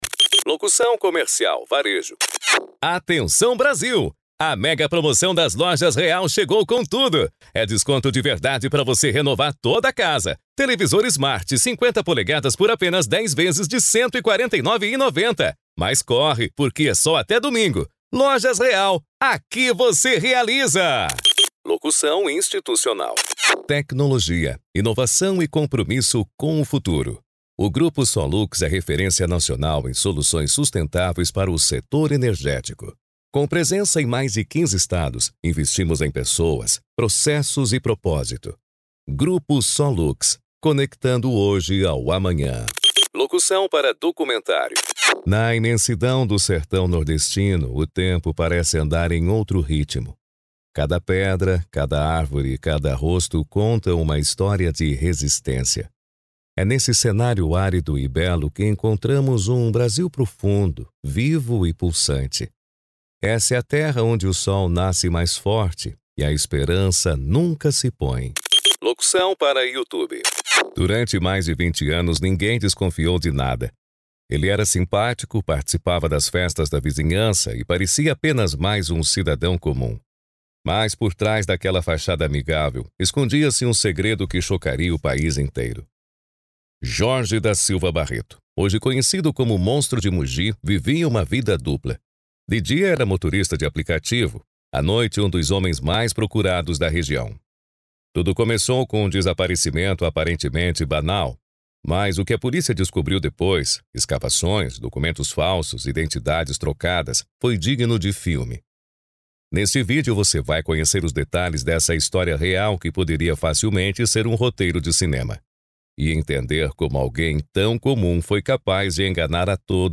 Spot Comercial
Vinhetas
VT Comercial
Impacto
Animada